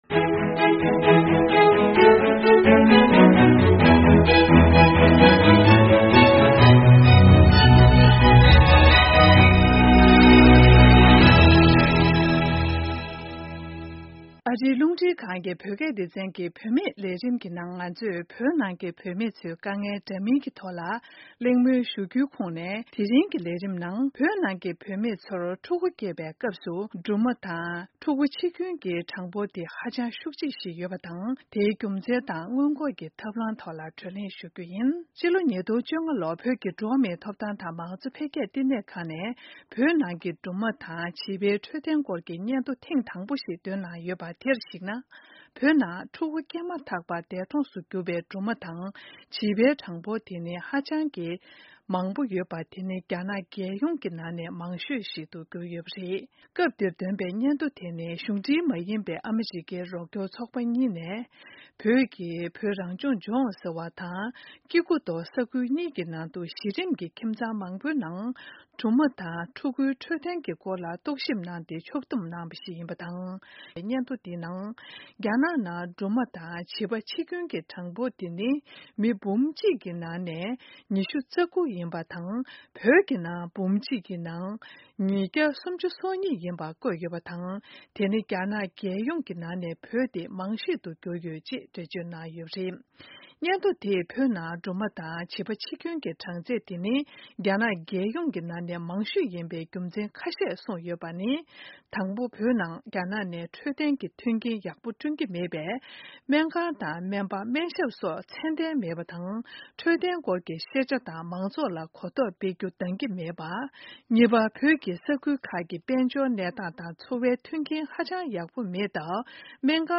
བཅའ་འདྲི་ཞུས་ཡོད།།